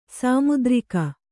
♪ sāmudrika